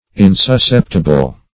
Insusceptible \In`sus*cep`ti*ble\, a. [Pref. in- not +